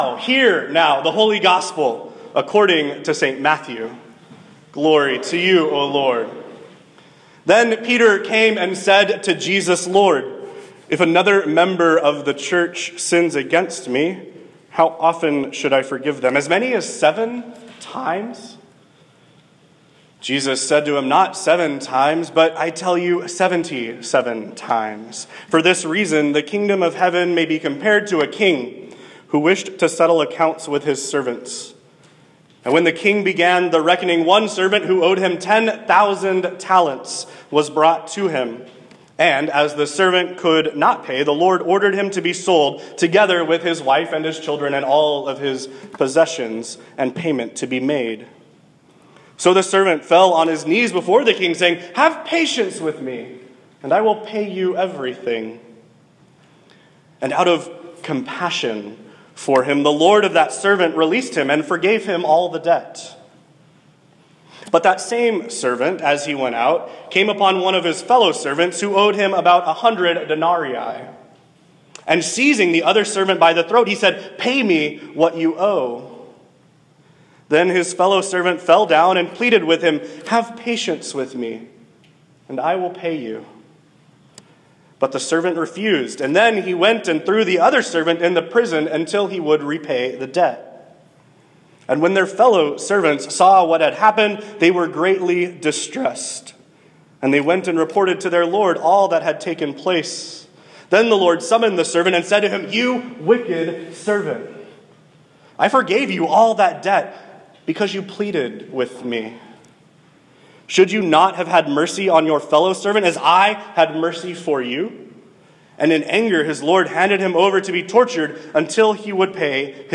* a sermon preached at New Hope Lutheran Church on September 17, 2017 *